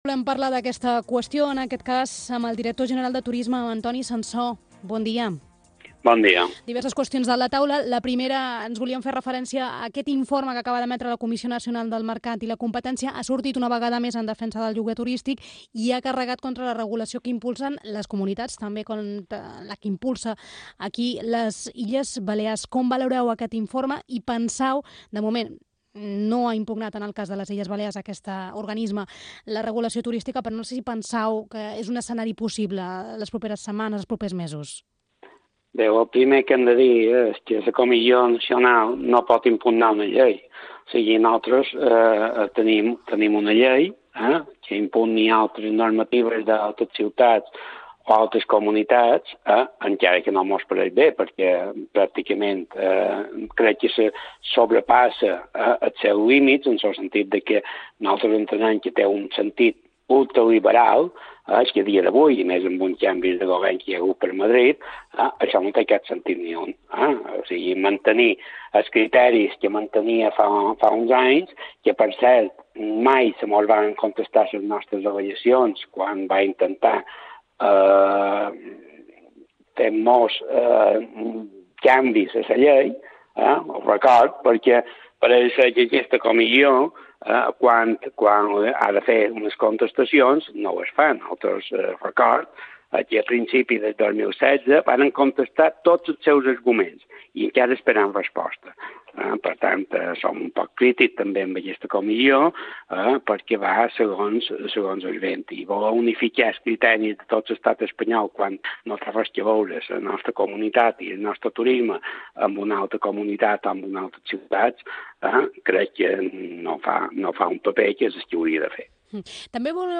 194-entrevista-sanso-ib3-radio.mp3